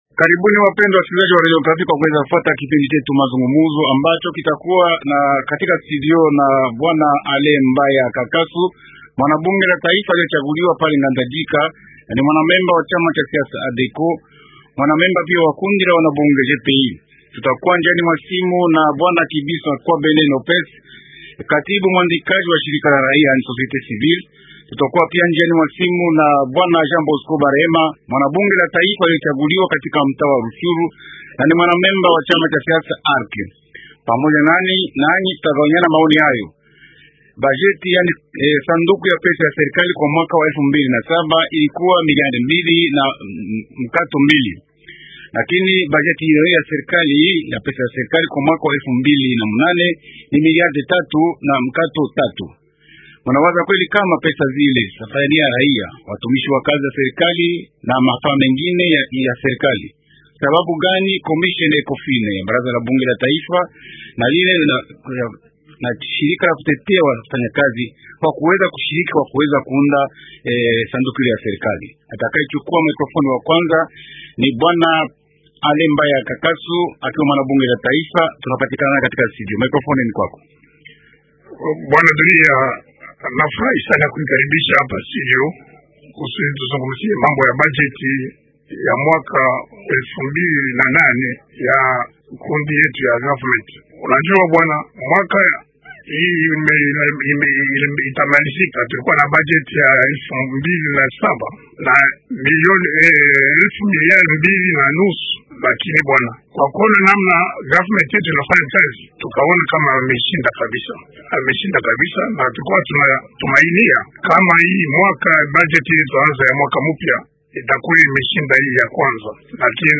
Waalikwa wa upinzani, wa kundi kubwa lenyi kuwa mdarakani na wale wa shirika la rahiya wanafikiri pamoja juu ya maoni hayo.